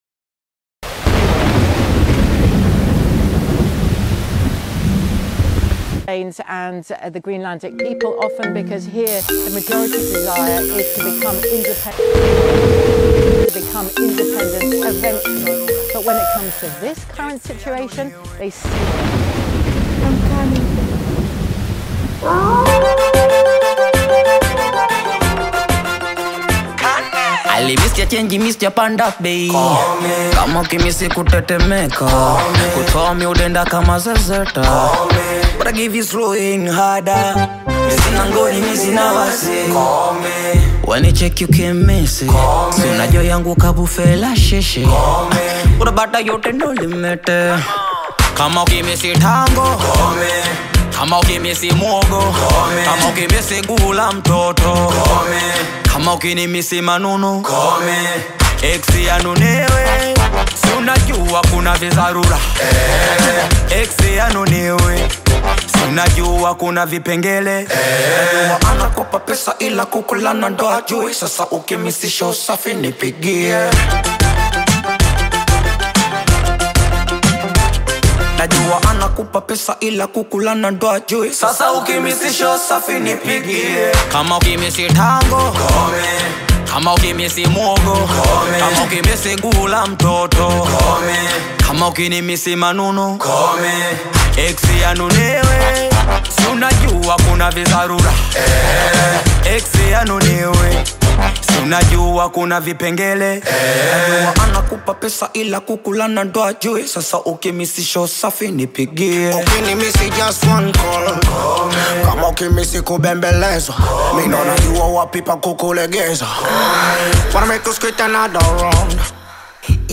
Tanzanian music duo
smooth vocals, and youthful energy